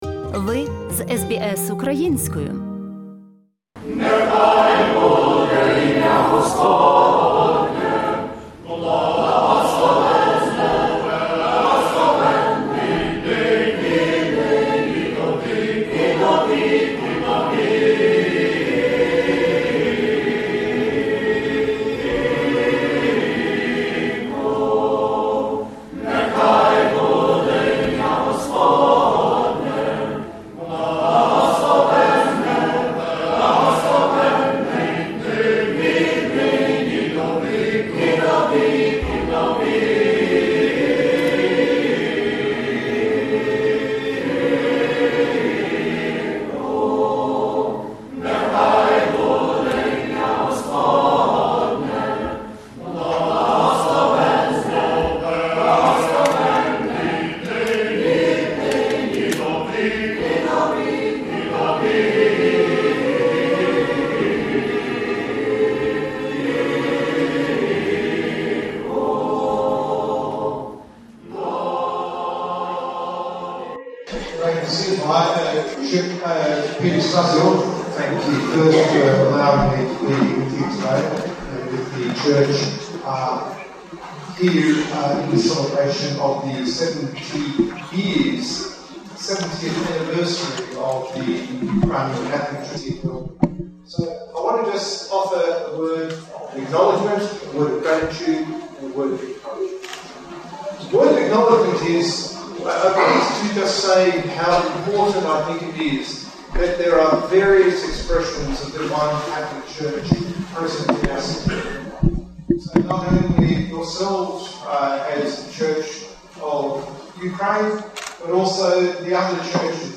у день святкування 70-річчя перебування
церква св. Августина, Мельбурн, Австралія
Прослухати слово високопреосвященного владики Пітера Коменсолі англійською мовою можна